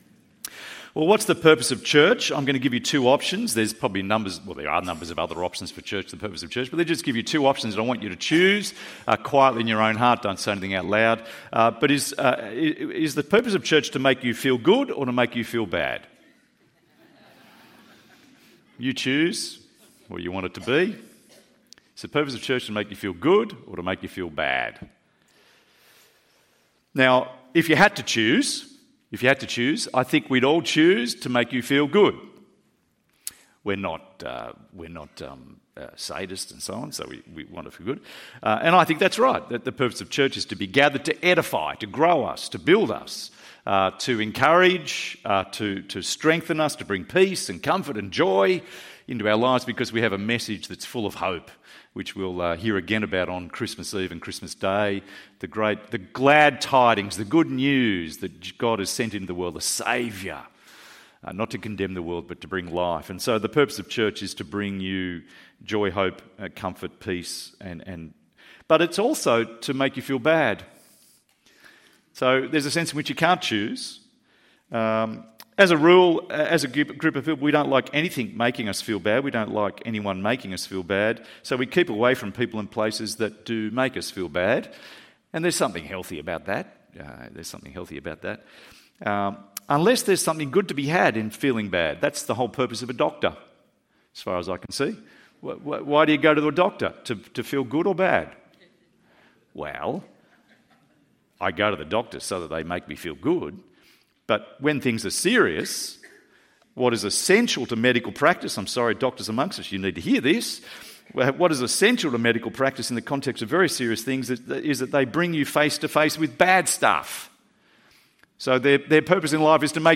Assurance in the Son (OR Life in the Son) ~ EV Church Sermons Podcast